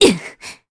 Aselica-Vox_Damage_kr_01.wav